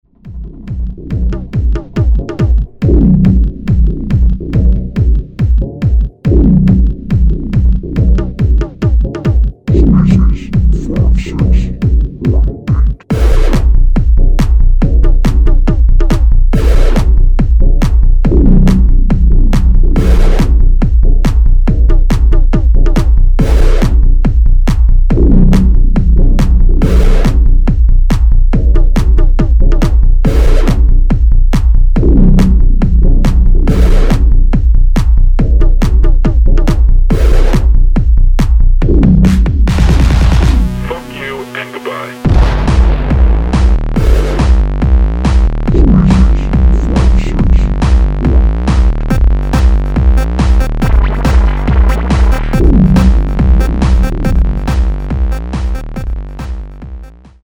die songs 8 bis 13 sind techno-tracks (140 bpm)